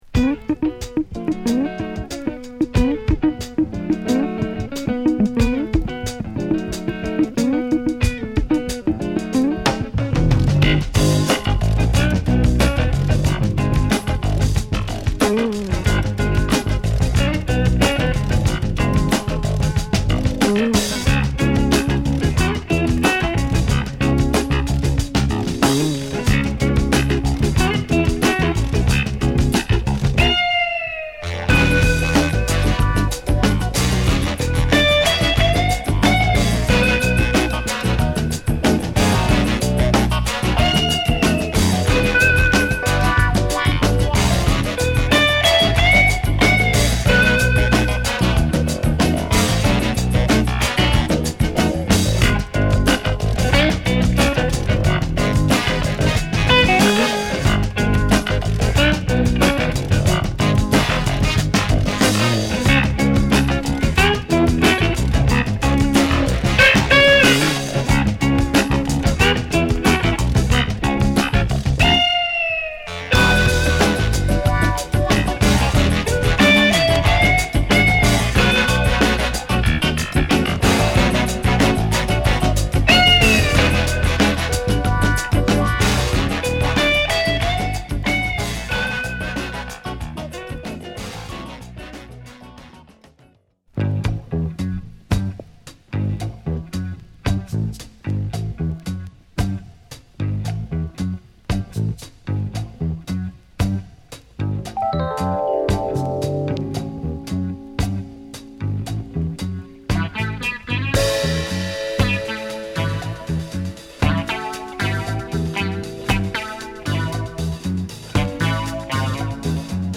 ブルース〜ジャズ・ファンク〜フュージョンを自由に行き来したアルバムです。